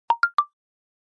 Huawei Bildirim Sesleri
Bongo Stock
Bongo_STOCK.mp3